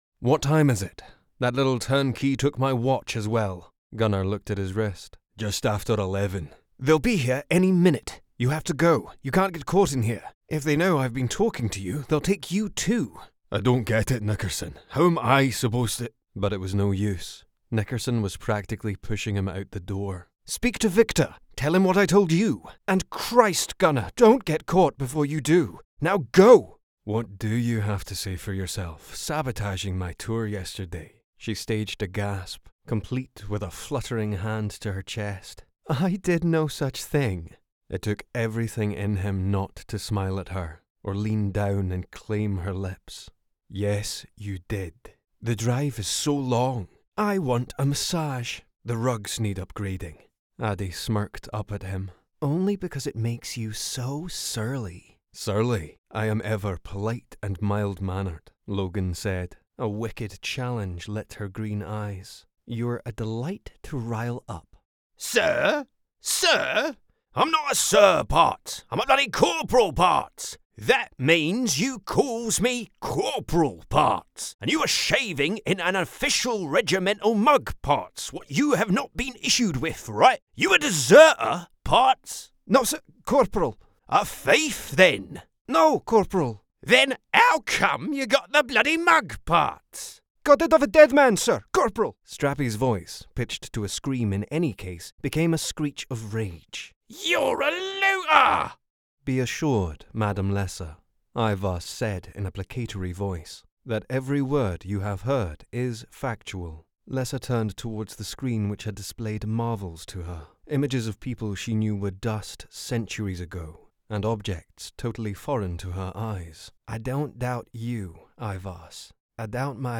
Audiobook Reel
• Native Accent: Scottish
• Home Studio